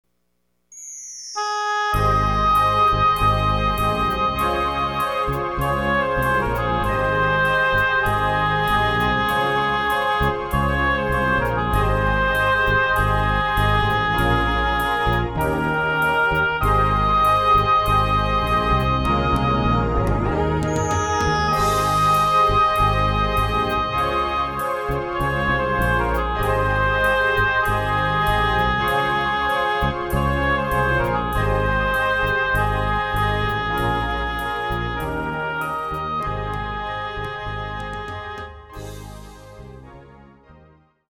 Location: Home Studio
Instrument: Various Synthesizers
Notes: This recording was made in my home studio.